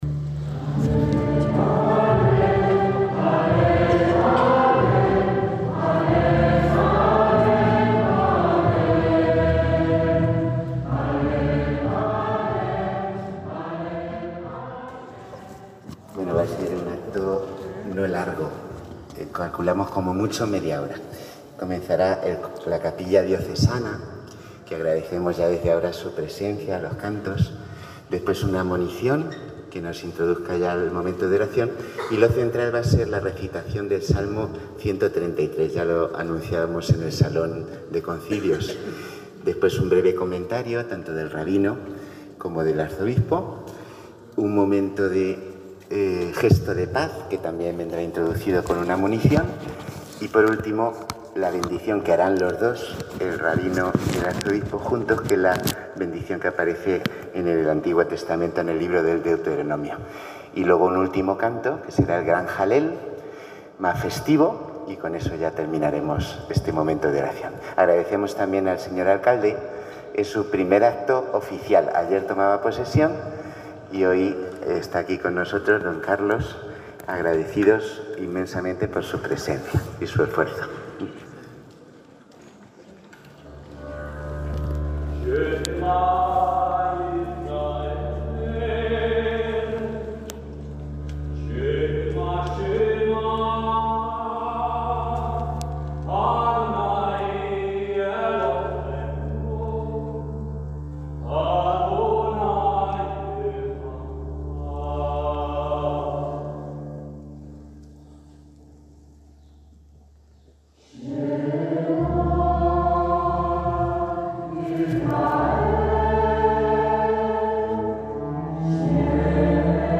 uno de los actos sin duda más emotivos fue la ceremonia conjunta de ambos credos en la recinto que fue la iglesia de Santa María La Blanca, antes sinagoga central de la ciudad